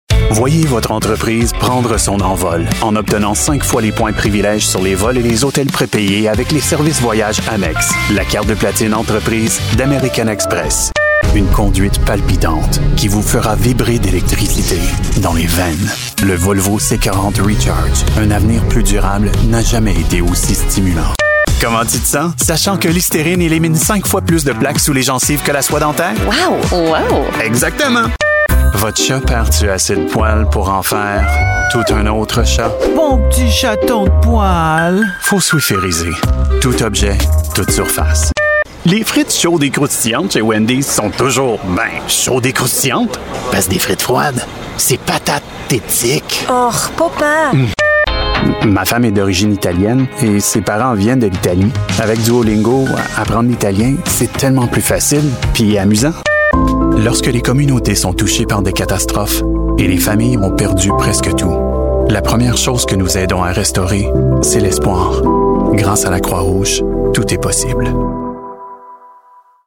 French Voice Over